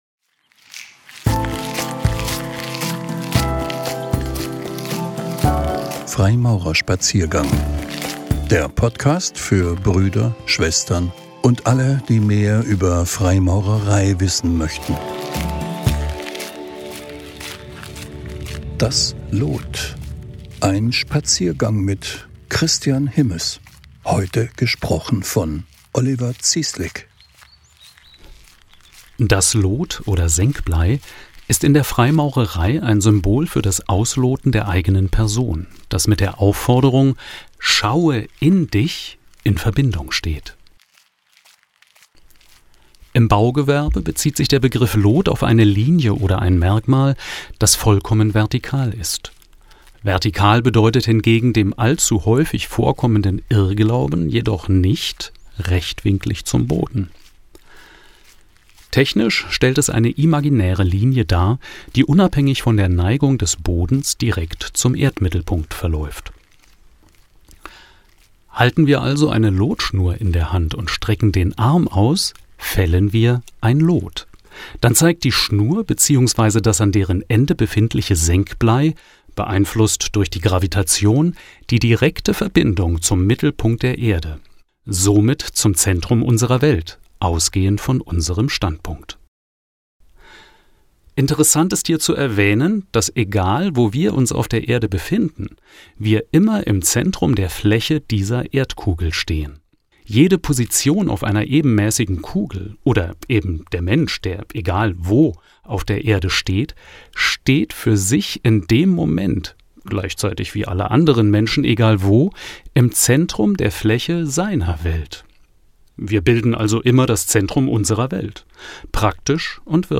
Ausgewählte "Zeichnungen" (Impulsvorträge) von Freimaurern.